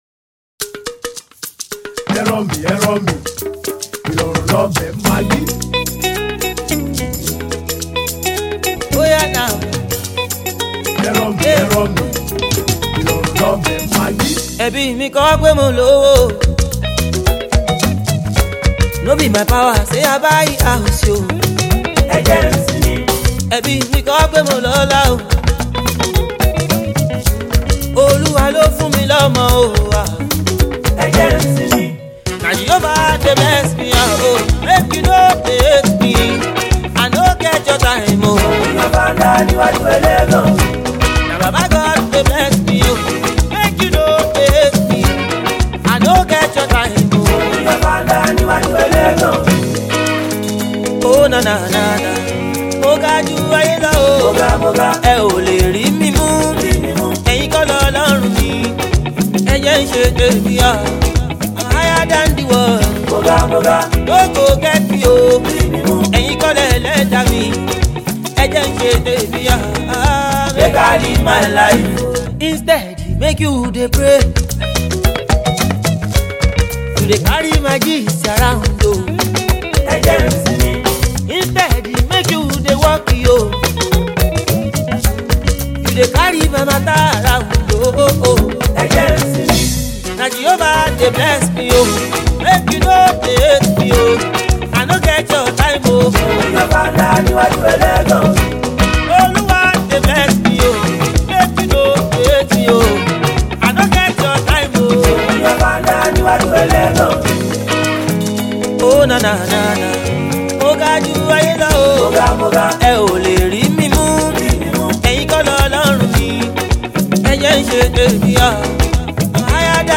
Urban-Highlife